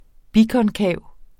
Udtale [ ˈbikʌnˌkæˀw ]